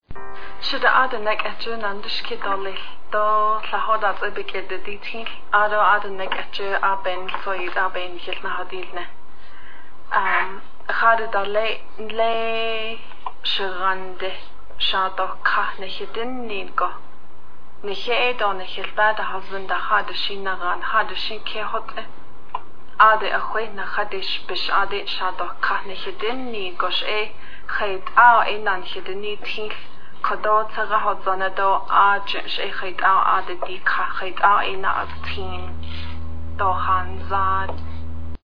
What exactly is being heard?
Interviewer: